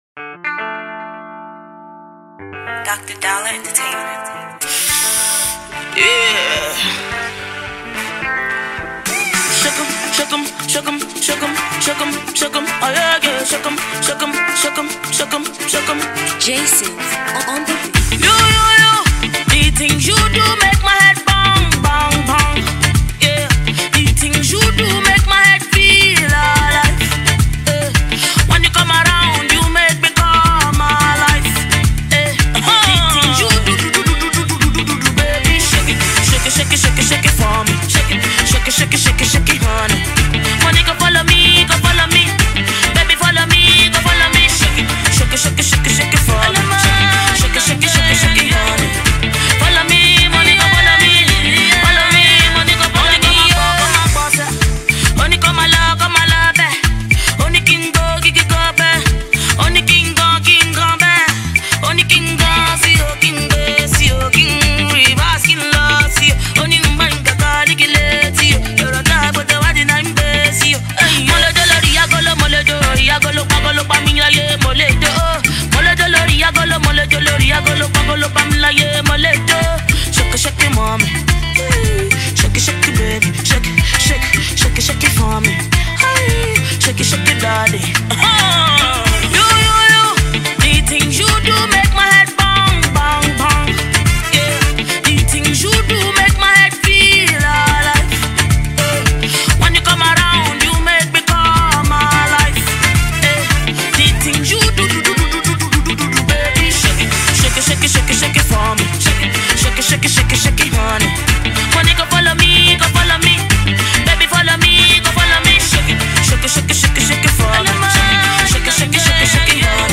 Afro-beat banger